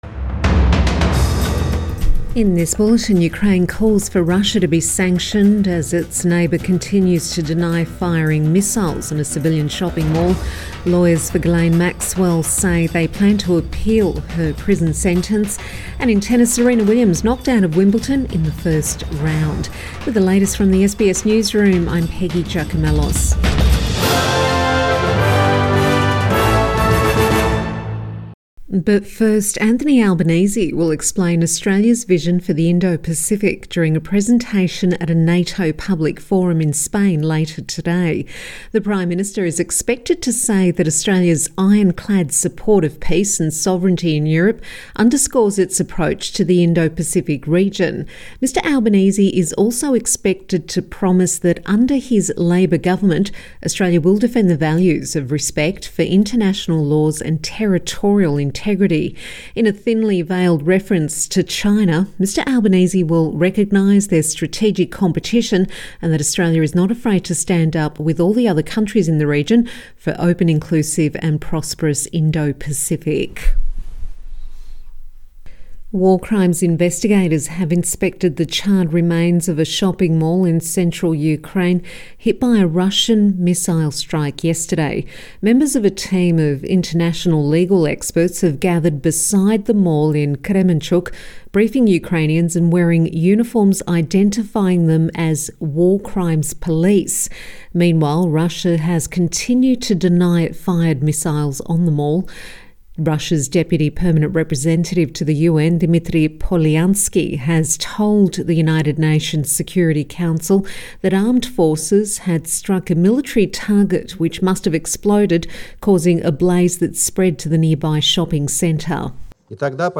Midday Bulletin 29 June 2021